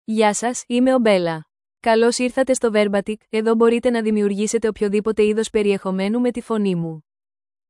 Bella — Female Greek (Greece) AI Voice | TTS, Voice Cloning & Video | Verbatik AI
Bella is a female AI voice for Greek (Greece).
Voice sample
Listen to Bella's female Greek voice.
Bella delivers clear pronunciation with authentic Greece Greek intonation, making your content sound professionally produced.